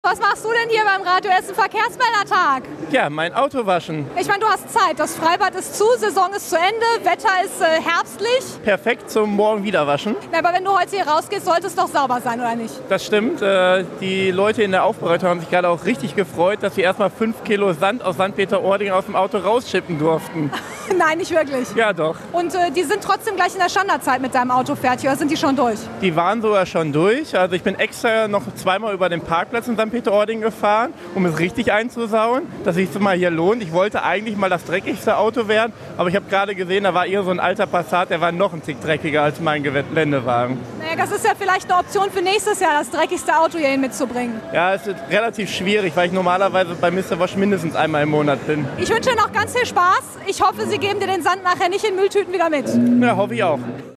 Der Radio Essen Verkehrsmeldertag 2019